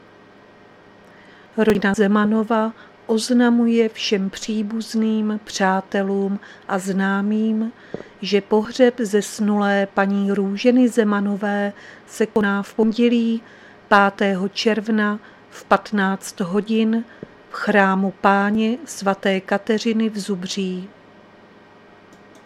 Záznam hlášení místního rozhlasu 2.6.2023